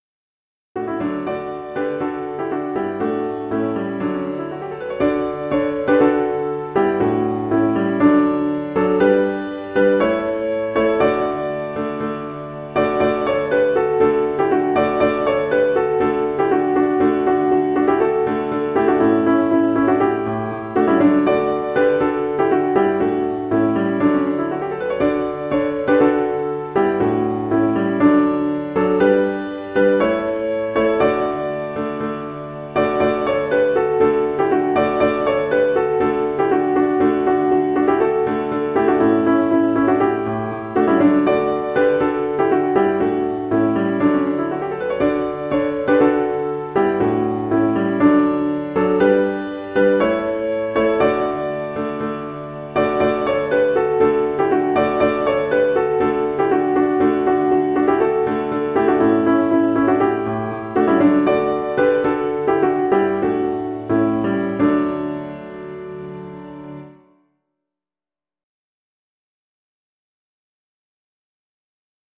Sheet Music - Vocals JoyToTheWorld2019-VOICE.pdf Sheet Music ALL audio Sop I audio Sop II audio Alto audio VA JoyToTheWorld2019_VA.mp3 rehearsal recording rehearsal recording rehearsal recording YouTube